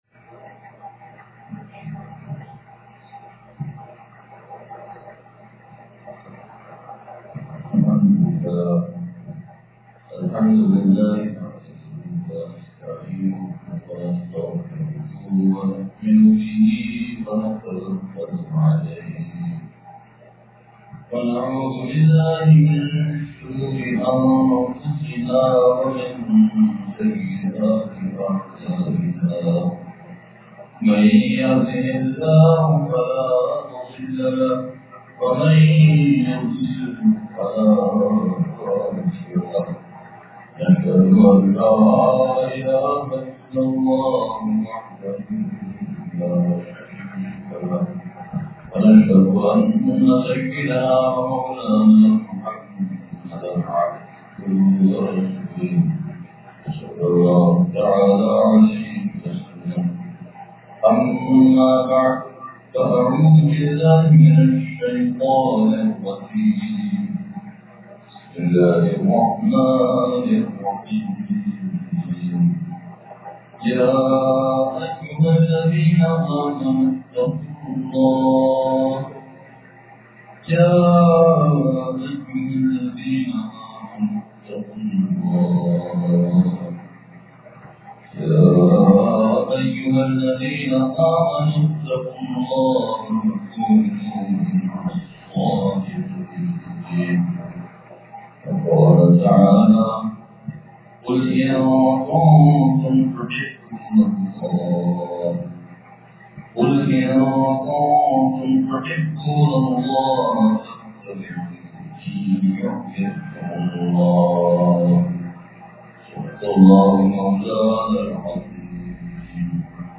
بیان بعد نمازِ مغرب حضرتِ والا دامت برکاتہم العالیہ کا اسلام آباد سے لائیو بیان ہوا